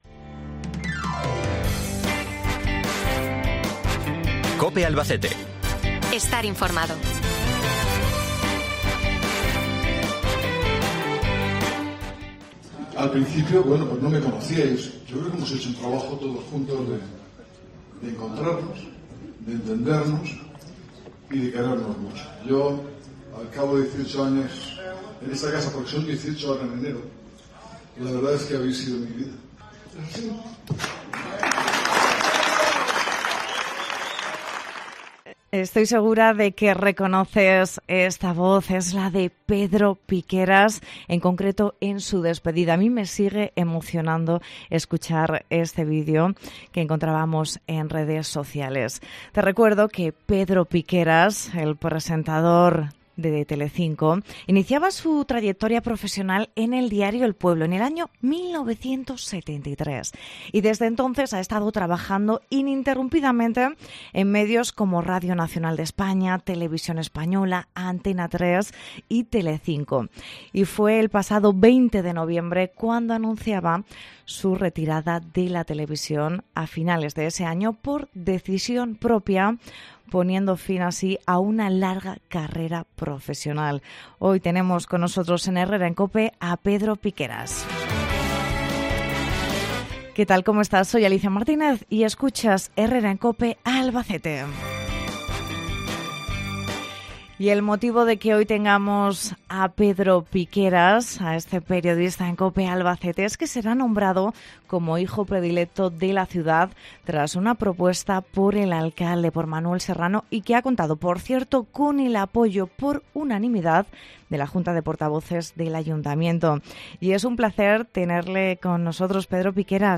Entrevista a Pedro Piqueras en Cope Albacete